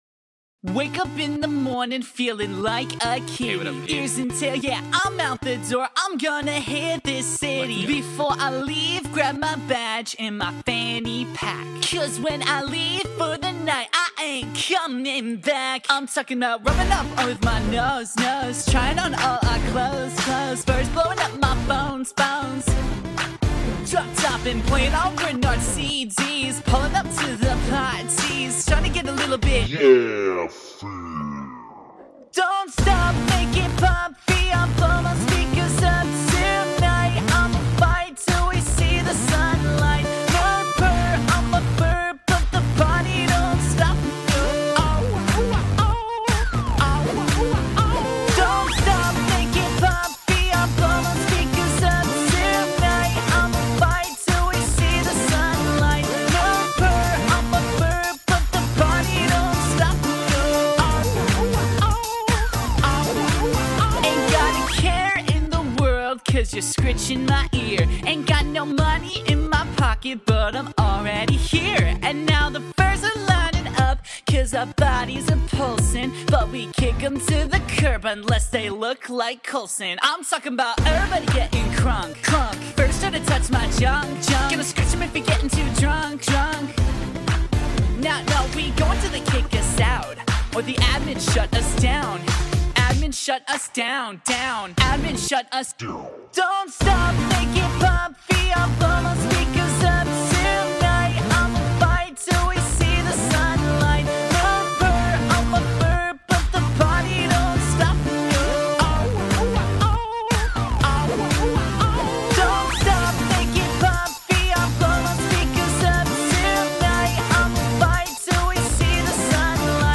EDIT: Wtf is with the growl at the end? o_O
Haha, that song was a parody.